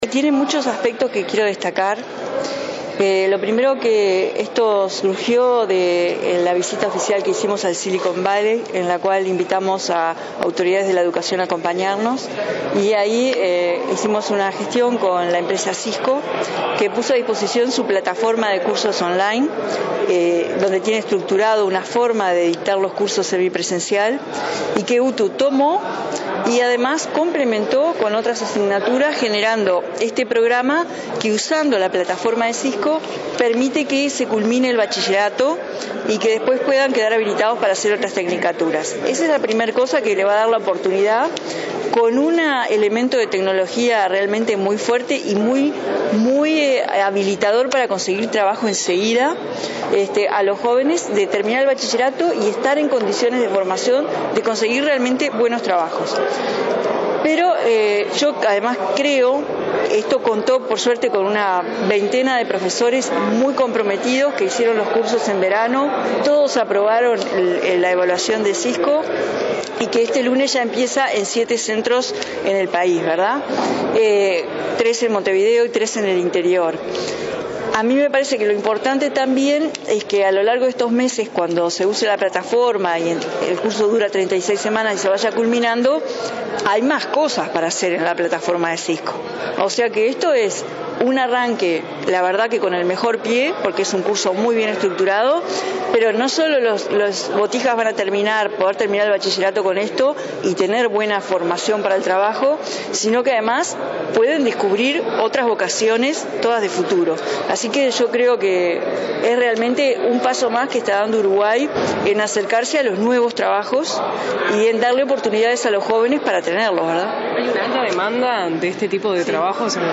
“Es un paso más para acercase a trabajos que tienen alta demanda y salarios un 27 % superiores”, afirmó la ministra de Industria, Carolina Cosse, en la presentación de la plataforma en línea que permitirá a estudiantes formarse en tecnología de la información.